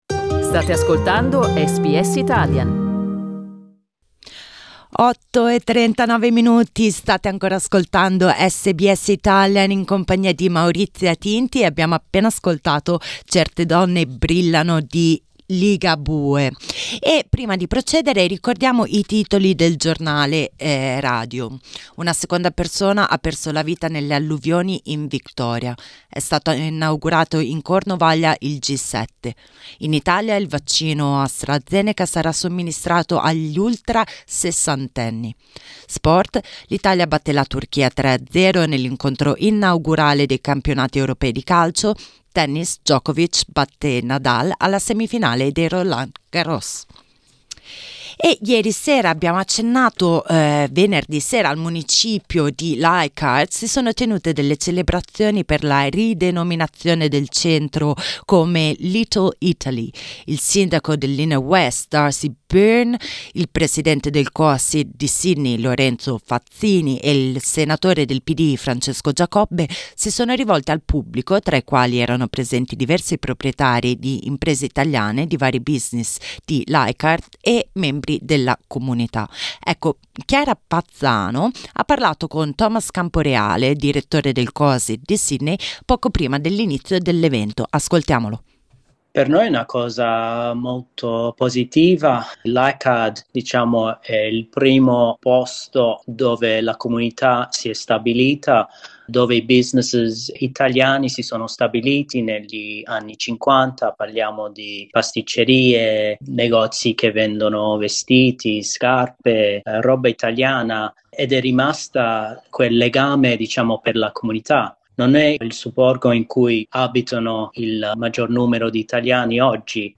Venerdì sera il centro di Leichhardt è ufficalmente diventato la Little Italy della capitale del NSW. SBS Italian era presente alla cerimonia.